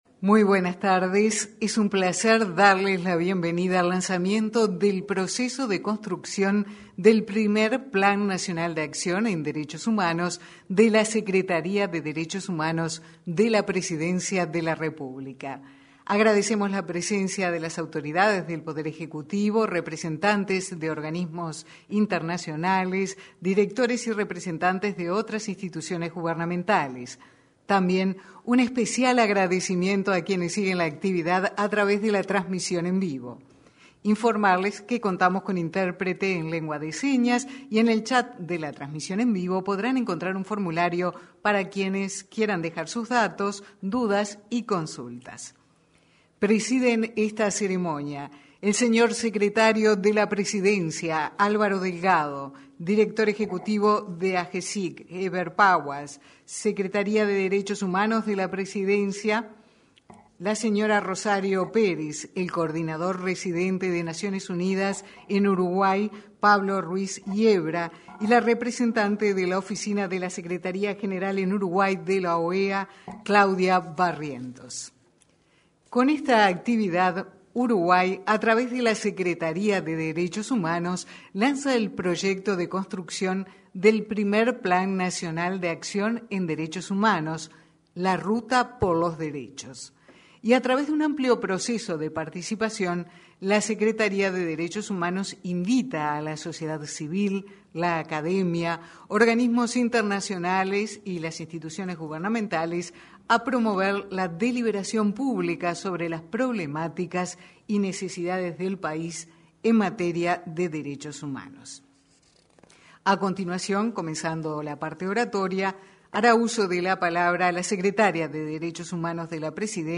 Lanzamiento del Plan Nacional de Acción en Derechos Humanos 20/08/2021 Compartir Facebook X Copiar enlace WhatsApp LinkedIn Este viernes 20, se desarrolló el lanzamiento del Plan Nacional de Acción en Derechos Humanos, realizado en el salón de actos de Torre Ejecutiva. En esa instancia, se expresó el secretario de Presidencia, Álvaro Delgado.